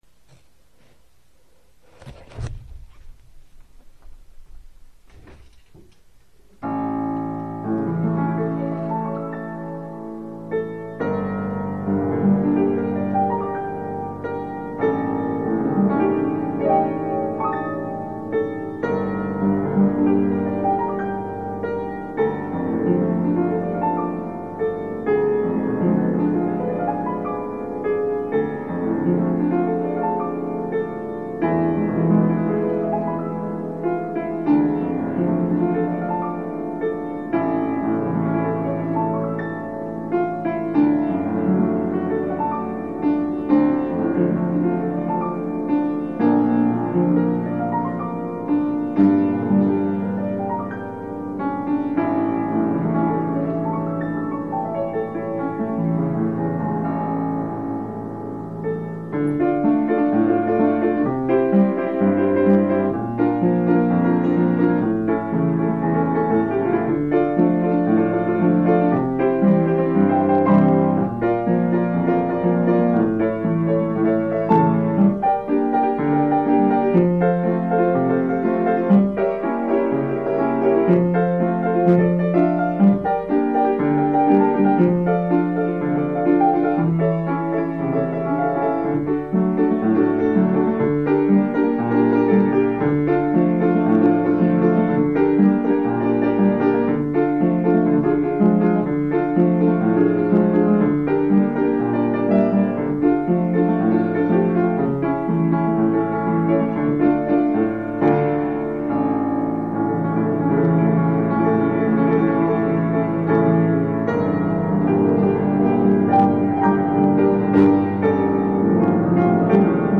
בפסנתר
איכות ההקלטה סבירה, אם כי לענ"ד ניתן היה לשפר אותה.